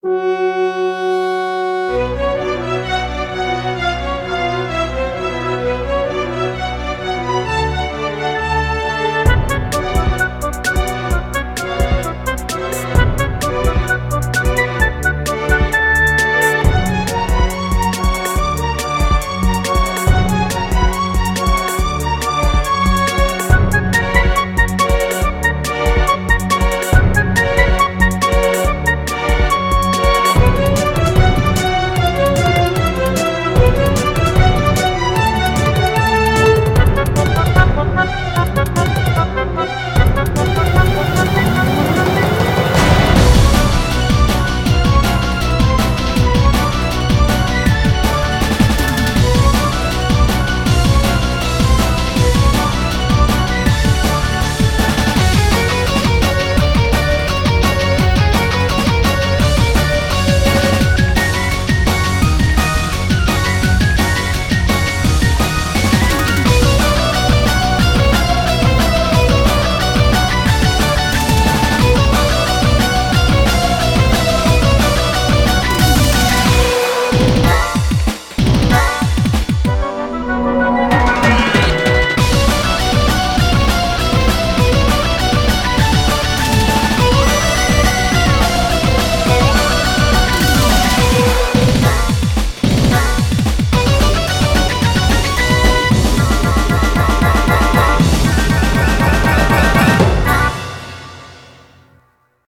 BPM130-240